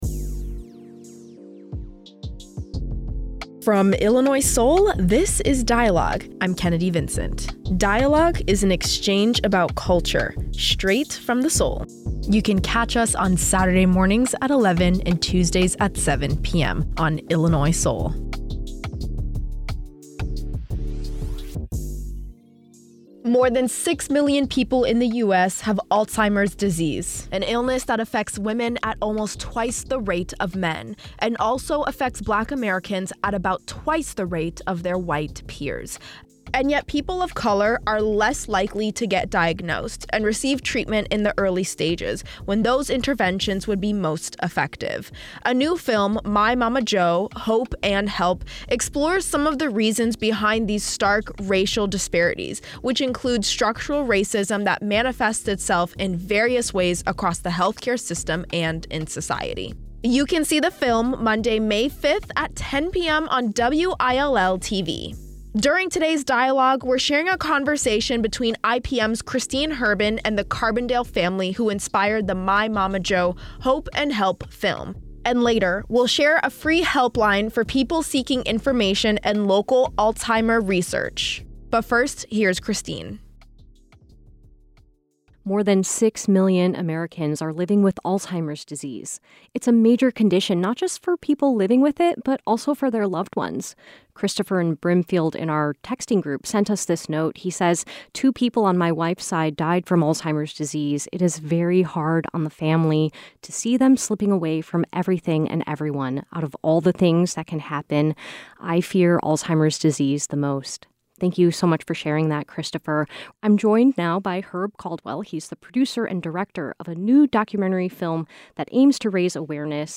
Then, two medical experts join the conversation to discuss health disparities, care and diagnosis.